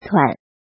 怎么读
tuǎn
tuan3.mp3